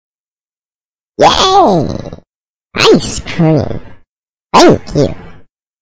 PLAY icecreamalertsound
icecream-alert_wUoA6Ys.mp3